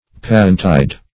Search Result for " passiontide" : The Collaborative International Dictionary of English v.0.48: Passiontide \Pas"sion*tide`\, n. [Passion + tide time.] The last fortnight of Lent.